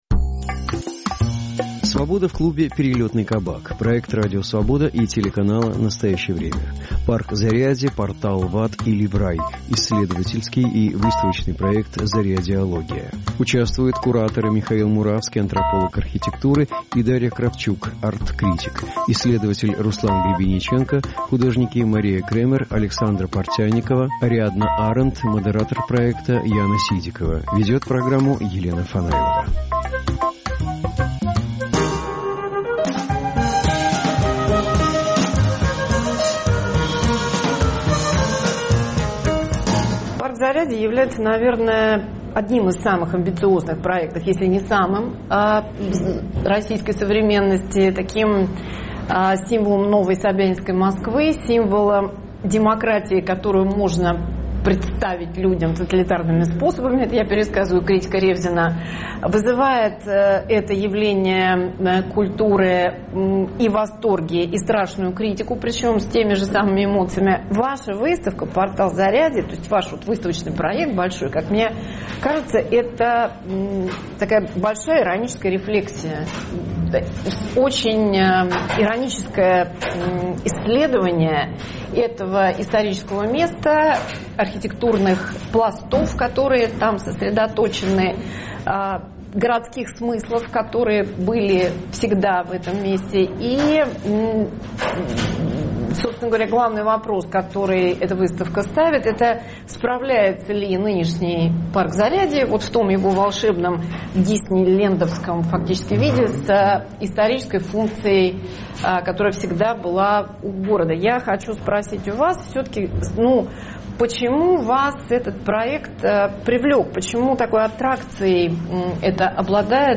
Стал ли парк Зарядье символом Москвы эпохи "управляемой демократии"? Разговор с исследователями архитектуры.